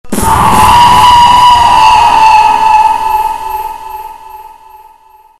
Sound Effects
Scary Scream 3